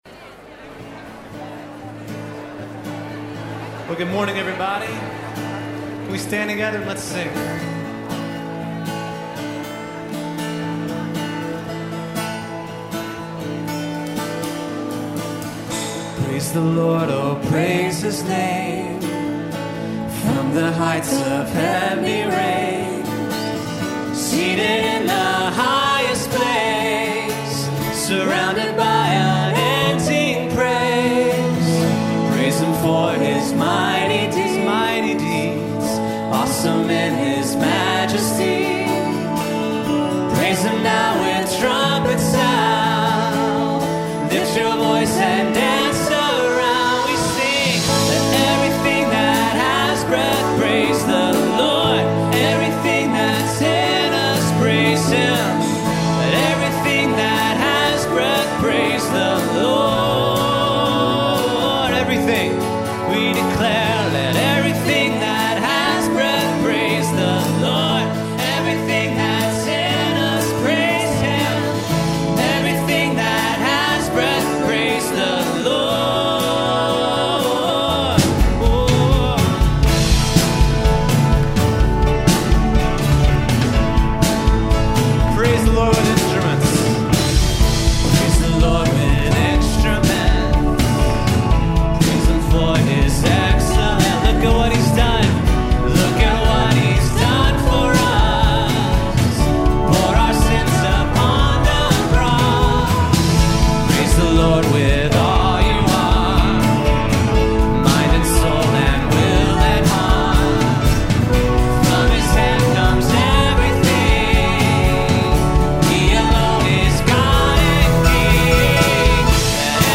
The first thing we did was start the opening song off in a way designed to help people join in. Instead of hitting them with a wall of sound, we took the first verse and chorus with just a few instruments and voices and then gradually ramped up.
Secondly, I tried to model an enthusiasm and eagerness in my singing and expressiveness.
Thirdly, I took a minute in the middle of the song to welcome people, explain why we were starting off in an upbeat way, invite them to clap with us, and encourage them to “shake off” the snow, the cold, the horrible traffic, and the historically bad commute from the previous Friday morning.
– I try to be gentle and confident.
– I don’t want to be a comedian, but I want to have a tone of good humor in my voice